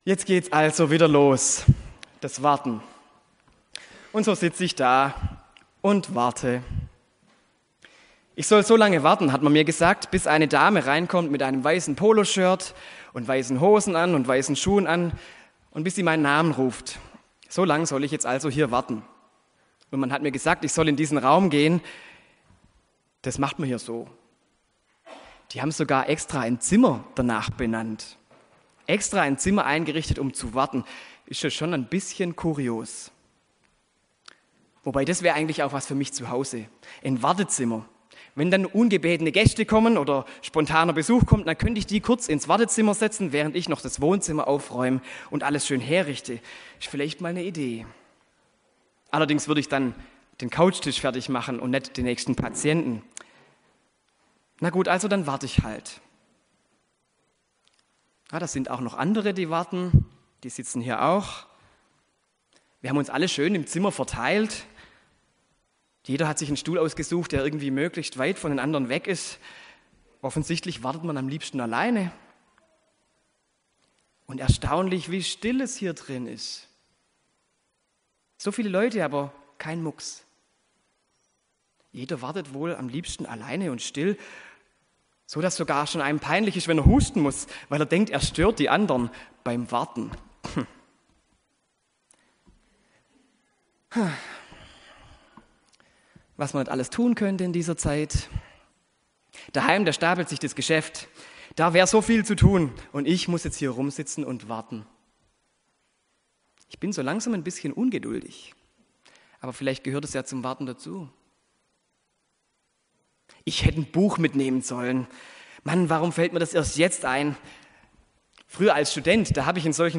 Predigt an Erntedank: Gefüllt ist der Obstkorb – Die Fülle der Gaben Gottes
Die Aufnahme ist vom Zweitgottesdienst mit Kindern des Kindergartens.